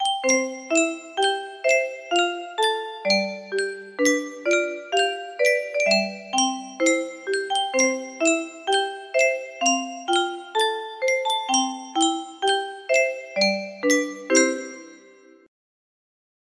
Yunsheng Music Box - Unknown Tune 1529 music box melody
Full range 60